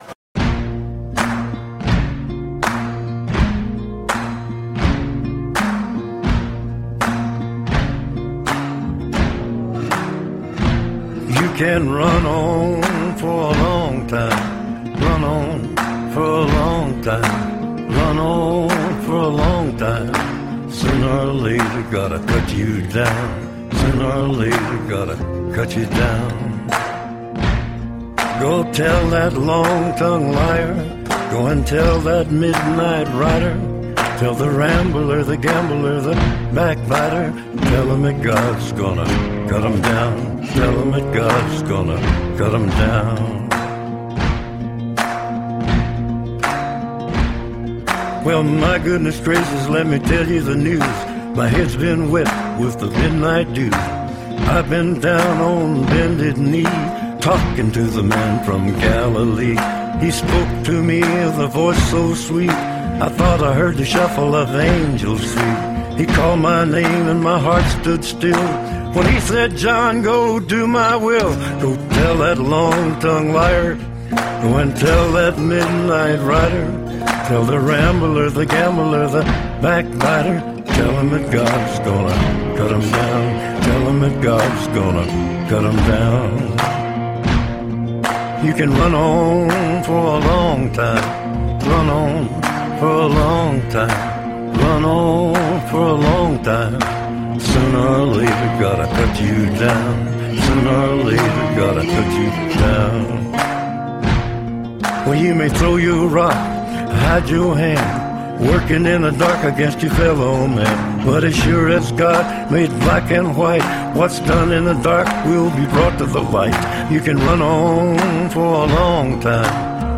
stara, folkowa ballada nieznanego autora
mamy tu tylko rytmiczne klaskanie i gitarę akustyczną.